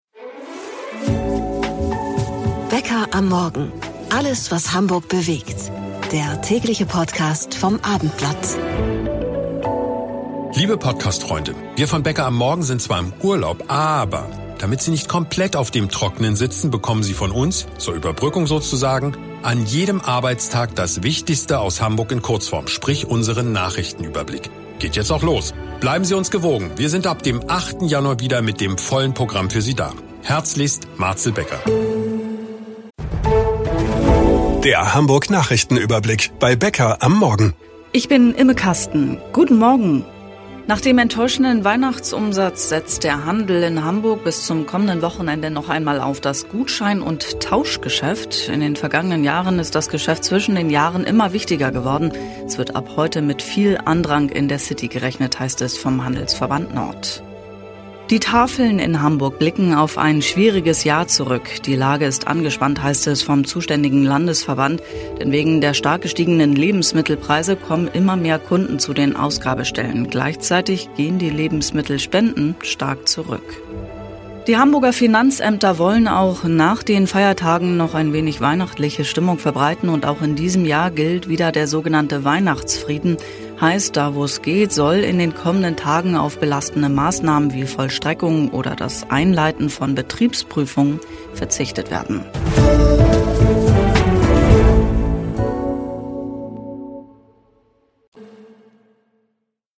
täglich für Euch - zur Überbrückung - der Nachrichtenüberblick!